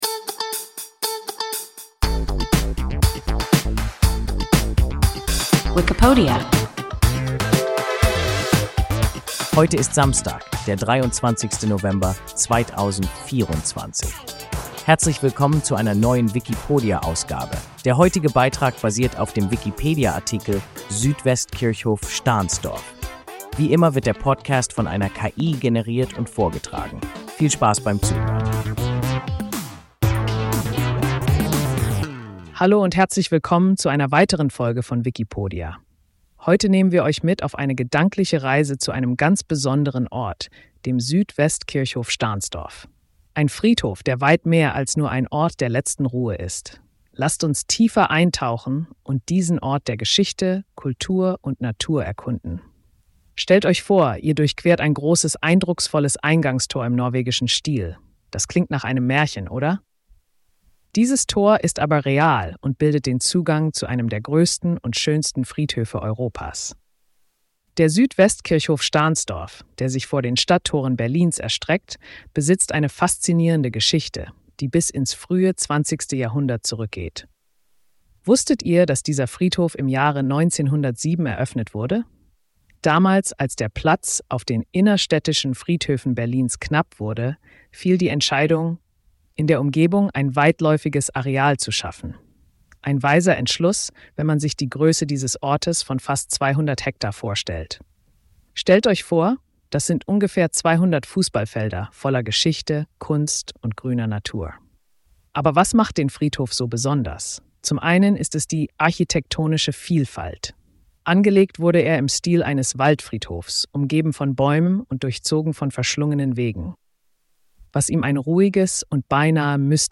Südwestkirchhof Stahnsdorf – WIKIPODIA – ein KI Podcast